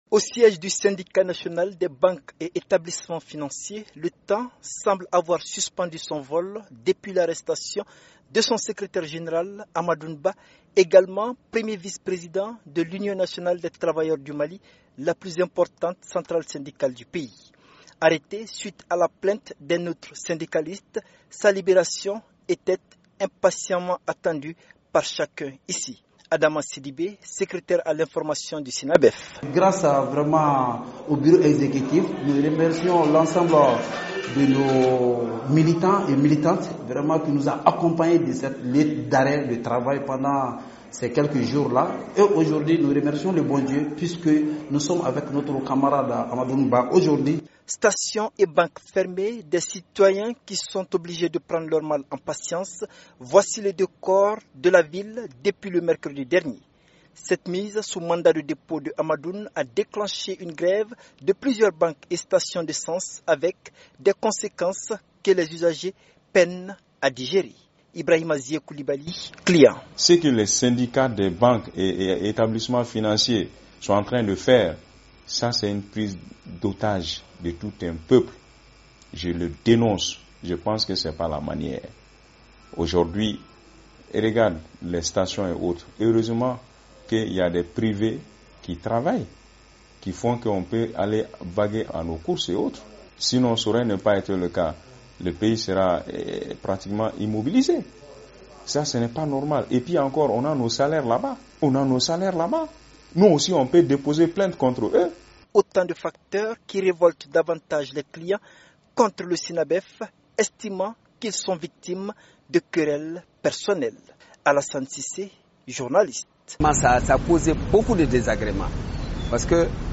Suite à la grève, les usagers ne décolèrent pas. Un reportage